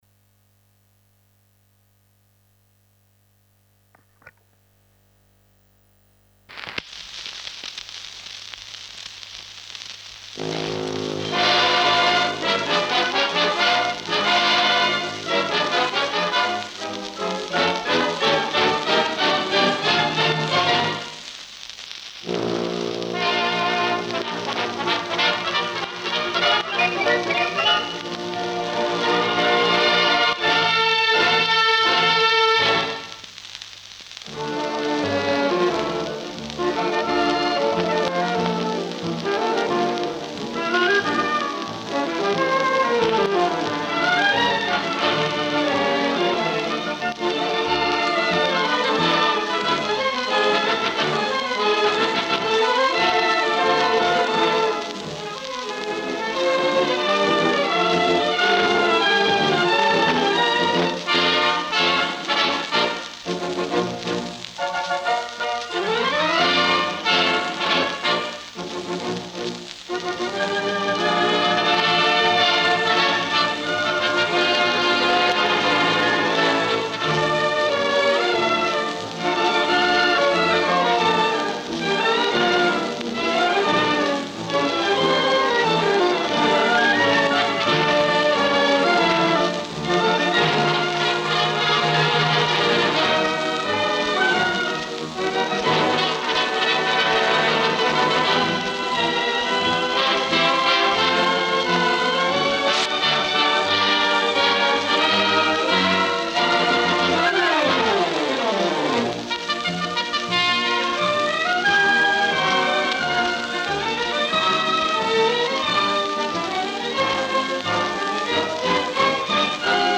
композитор, аранжировщик, дирижёр.